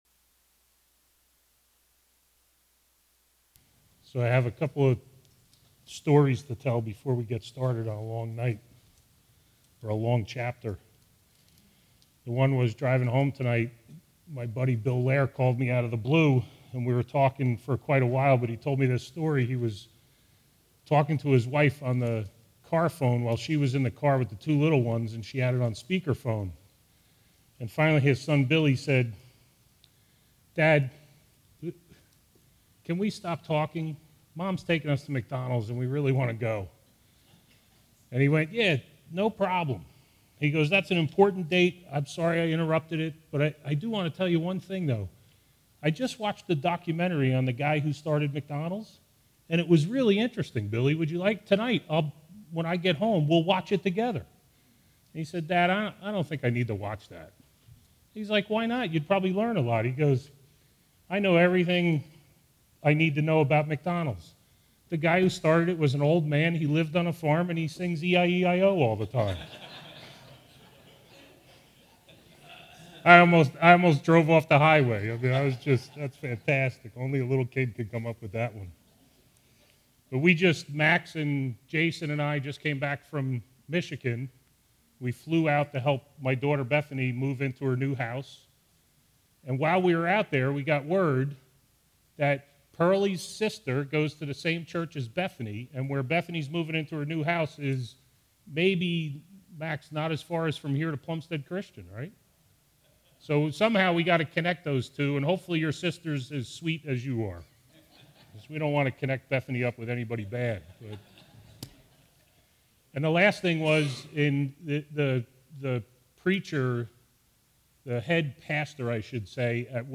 All Sermons Judges 9